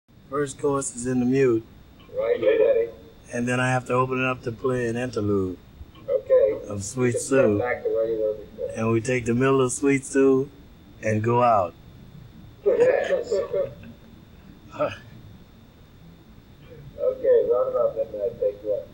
Unreleased Stusio Session Of New Yourk City 1955 - 1956